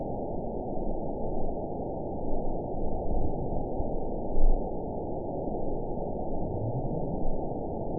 event 912514 date 03/28/22 time 09:34:47 GMT (3 years, 1 month ago) score 8.98 location TSS-AB03 detected by nrw target species NRW annotations +NRW Spectrogram: Frequency (kHz) vs. Time (s) audio not available .wav